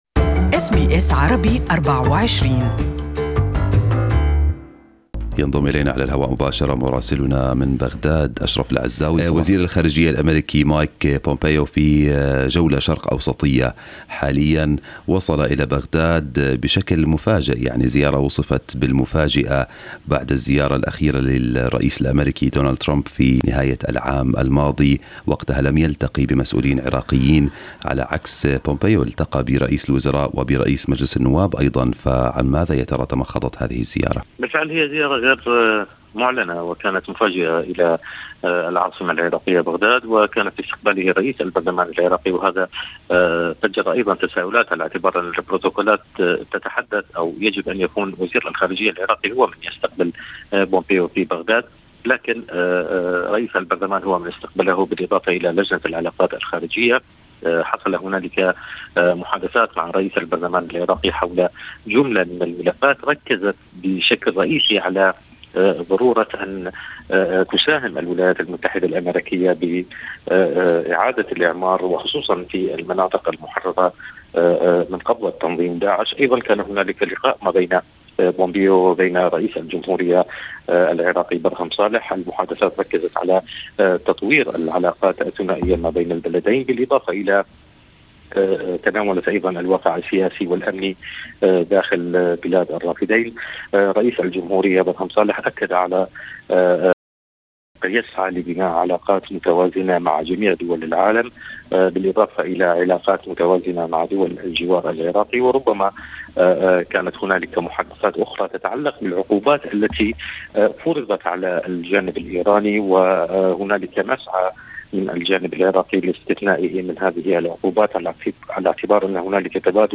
أبرز أخبار العراق في أسبوع